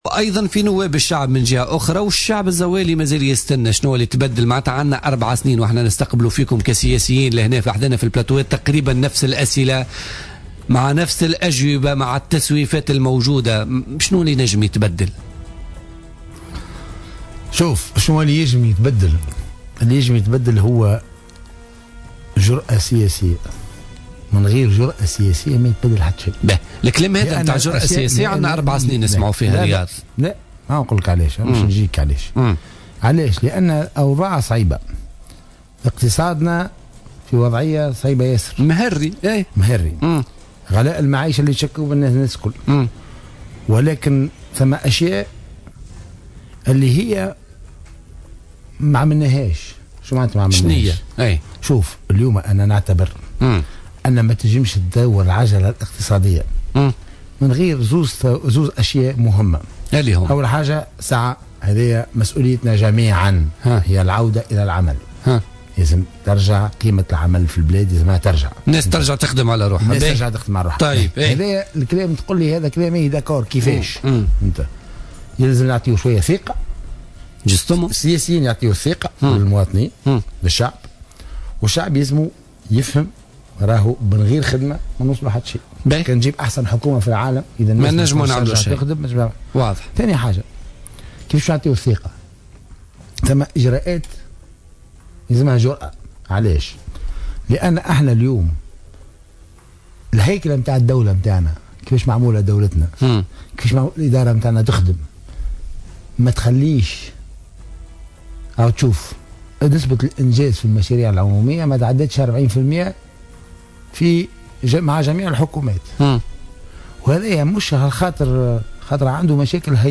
قال القيادي في حزب آفاق تونس،رياض المؤخر ضيف برنامج "بوليتيكا" اليوم إن حكومة الحبيب الصيد لها الدعم السياسي كي تتخذ القرارات الجريئة التي تحتاج إليها البلاد في ظل الأوضاع الاقتصادية الصعبة التي تمر بها البلاد من ارتفاع للأسعار وغلاء للمعيشة وغيرها